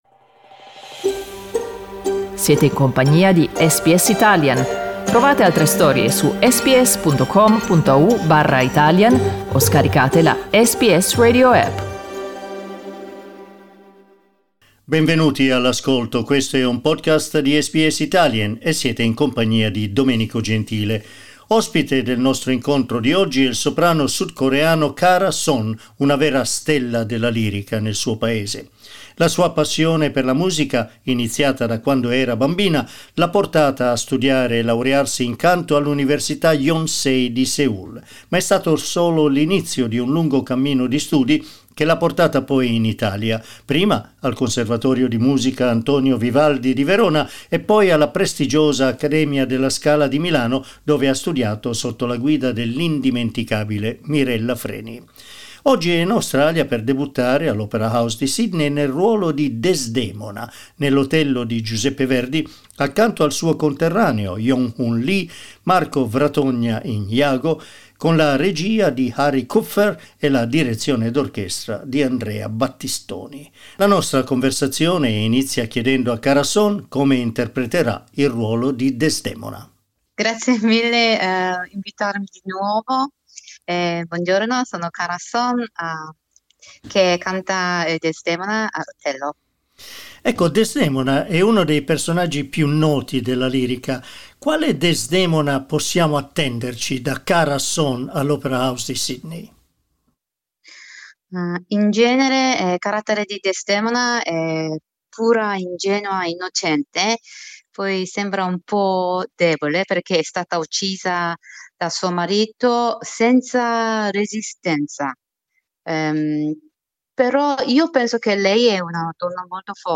Ascolta l'intervista con Karah Son: